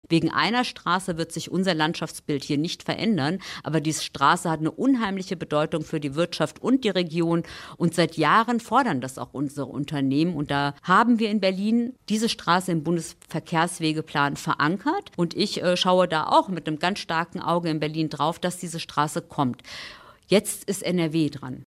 Deshalb haben wir unsere SPD-Bundestagsabgeordnete auf dieses Thema in unserem Radio Siegen-Sommer-Interview angesprochen.